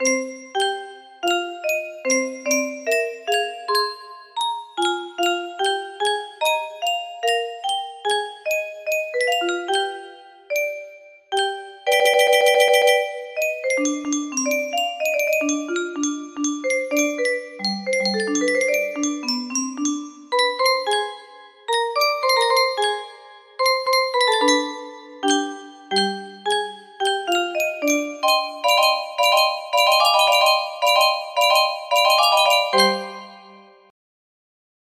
Game intro simple